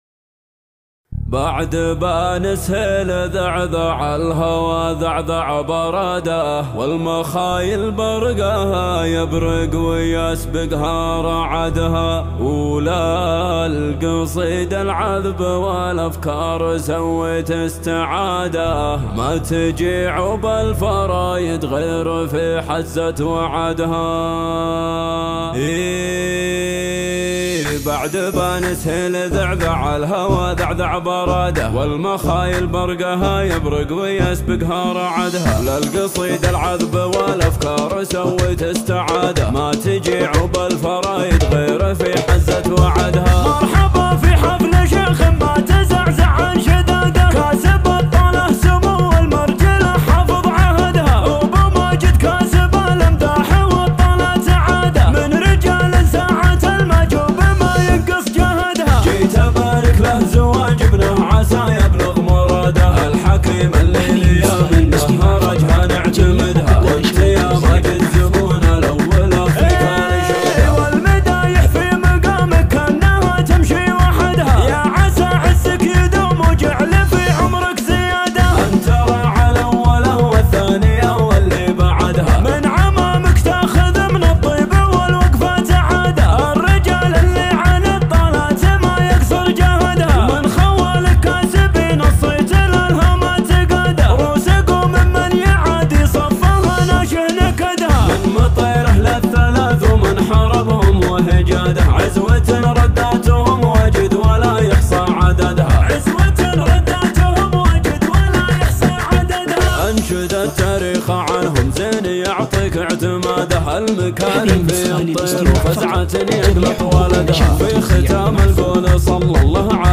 زفة